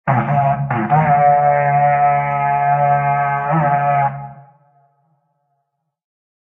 raidhorn_02.ogg